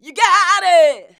YOU GOT IT.wav